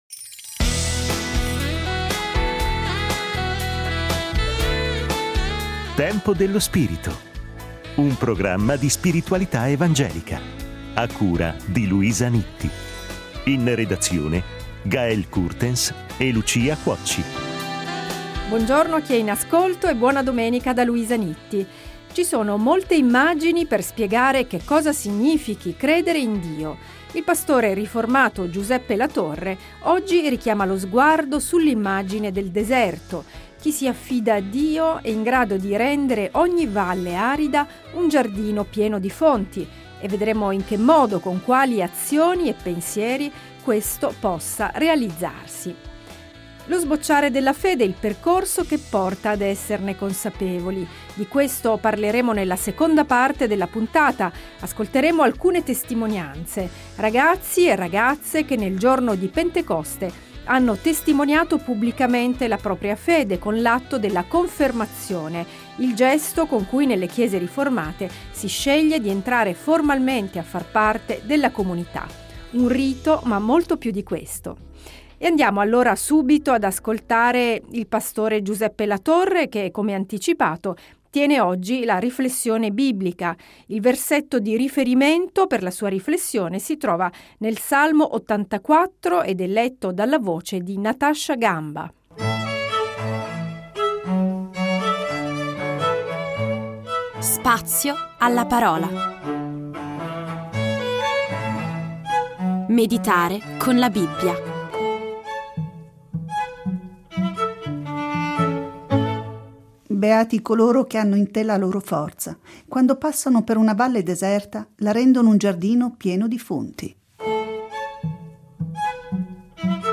Ascoltiamo le loro testimonianze, le loro parole che mostrano lo sbocciare della loro fede e il desiderio di accogliere Dio nella propria vita.